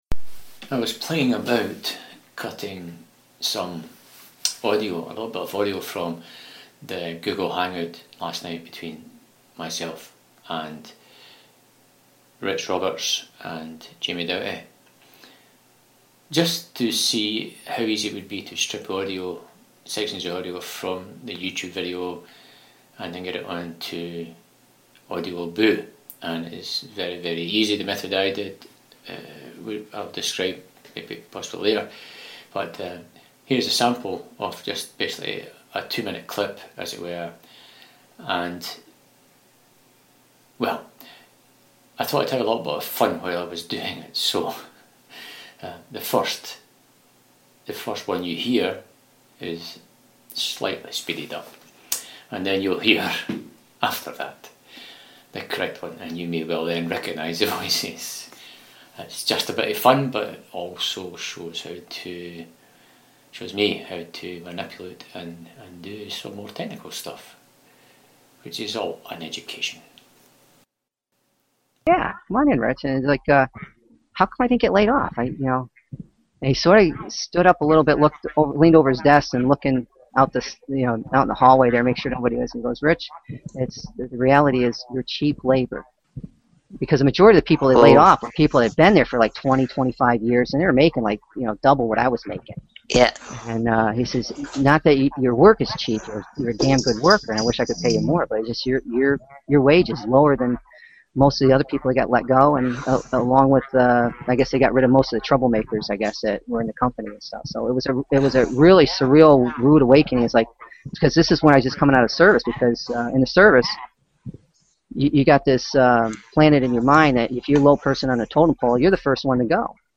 Using a file download programme to strip out desired pieces of audio from any Youtube video, to use for an audioboo. I didn't at this stage refine or edit the "cutting" which could be done pre upload... though I couldn't resist the temptation to mess about a bit.